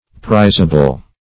prizable - definition of prizable - synonyms, pronunciation, spelling from Free Dictionary
prizable - definition of prizable - synonyms, pronunciation, spelling from Free Dictionary Search Result for " prizable" : The Collaborative International Dictionary of English v.0.48: Prizable \Priz"a*ble\, a. Valuable.
prizable.mp3